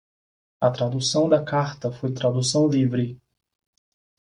Pronounced as (IPA) /ˈkaʁ.tɐ/